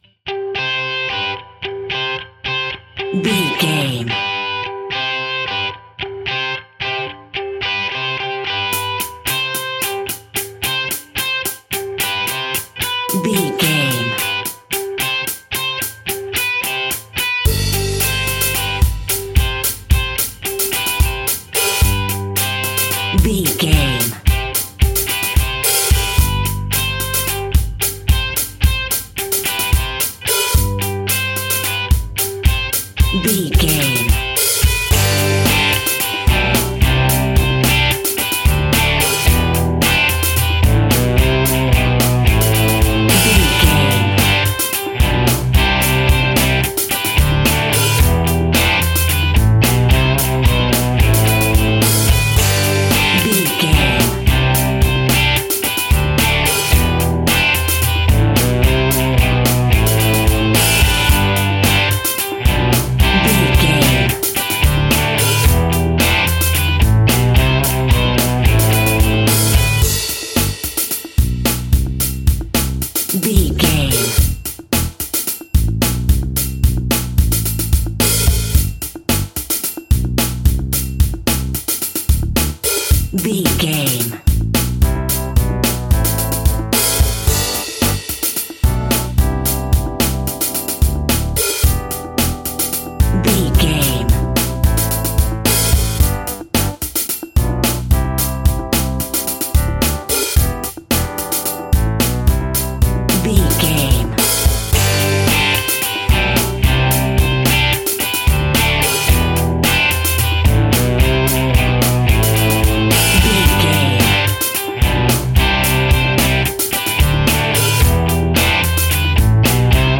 Fast paced
Ionian/Major
pop rock
indie rock
energetic
uplifting
Instrumental rock
distortion
electric guitar
bass guitar
drums